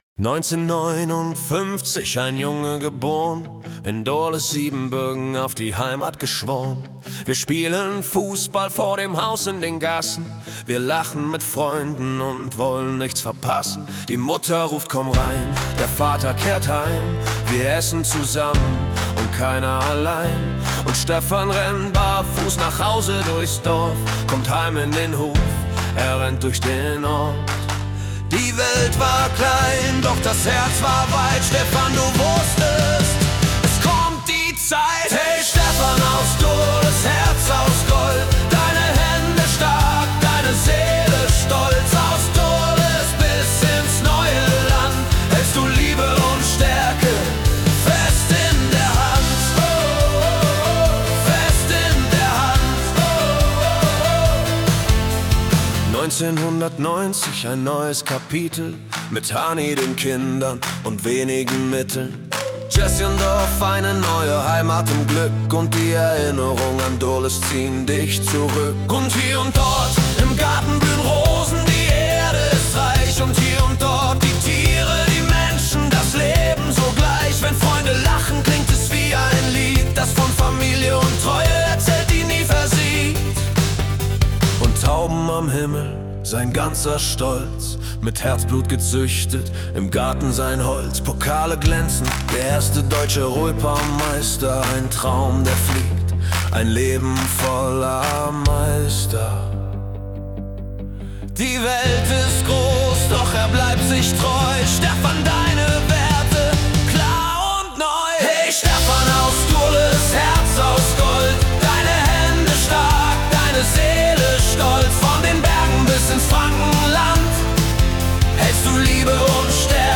Rock
• KI-generierte Melodie